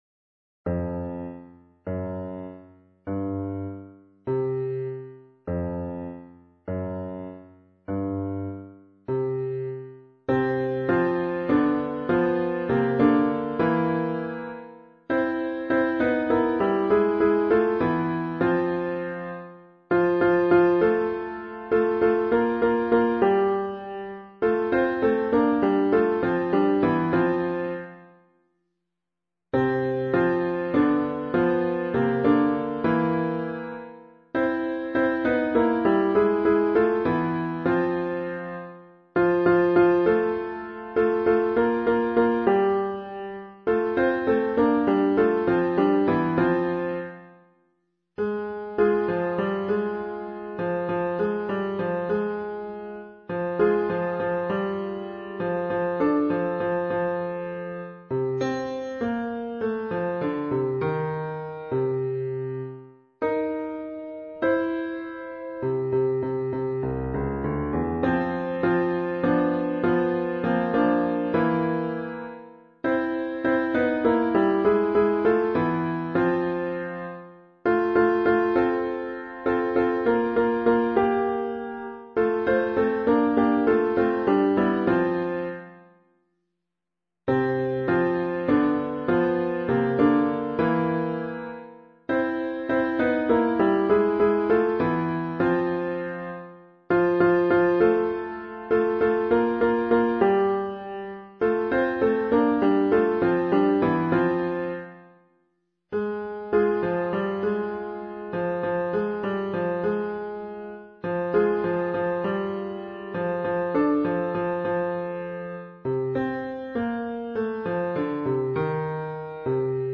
Full score Full recording Audio files: Soprano | Alto | Bass | SAB (all three) We’d like our performance to have a smooth jazzy style, so let’s take inspiration from the wonderful Manhattan Transfer .
Java Jive-SAB.mp3